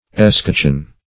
escocheon - definition of escocheon - synonyms, pronunciation, spelling from Free Dictionary Search Result for " escocheon" : The Collaborative International Dictionary of English v.0.48: Escocheon \Es*coch"eon\, n. Escutcheon.